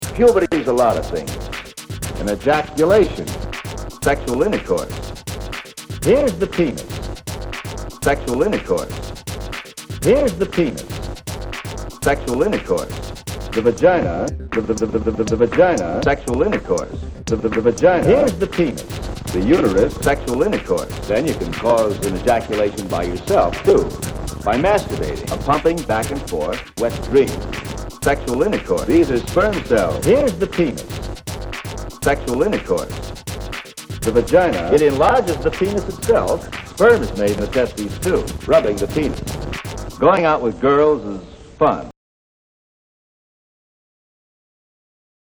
My first try at using dialog samples in a GarageBand song.